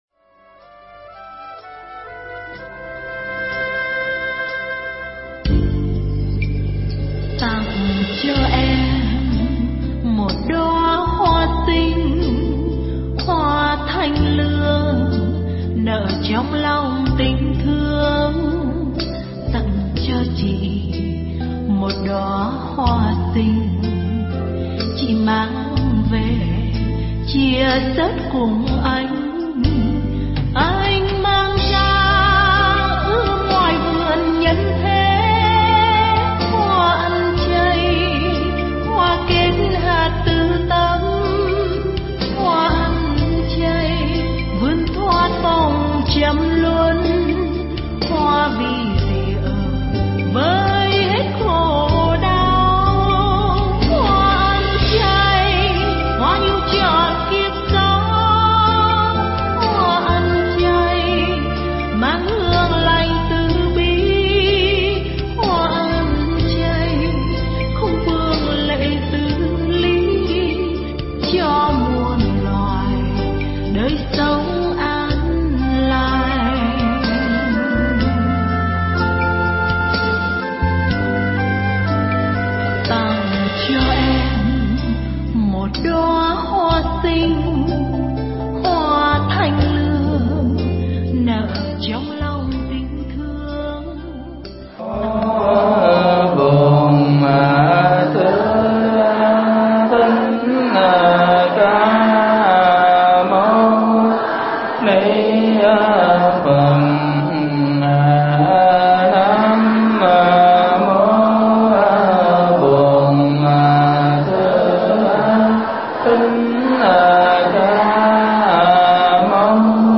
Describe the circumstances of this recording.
giảng tại chùa Phật Học quận Ninh Kiều thành phố Cần Thơ